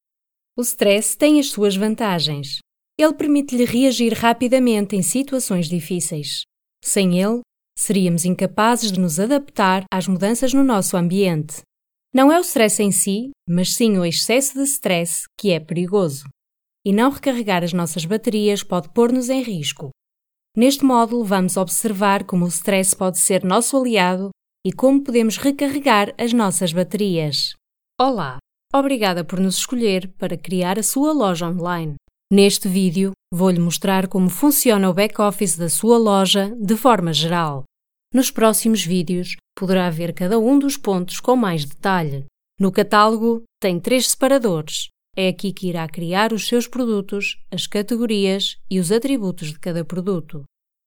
西班牙语配音
• 女西102T 西班牙语女声 Female 1 - DEMO - EU Portuguese 低沉|激情激昂|大气浑厚磁性|沉稳|娓娓道来|科技感|积极向上|时尚活力|神秘性感|调性走心|亲切甜美|感人煽情|素人